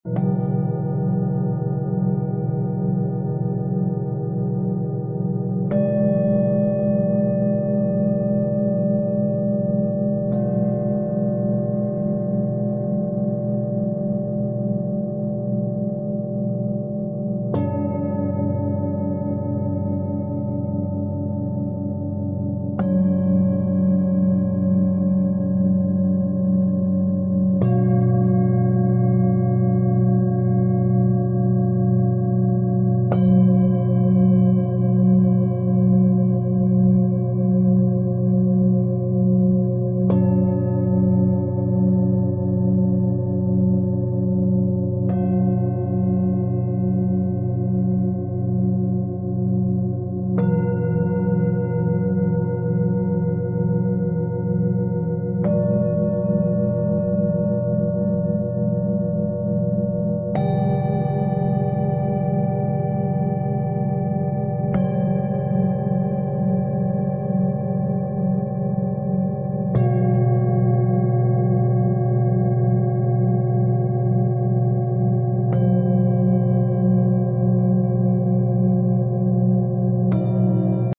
Sound Bath Recording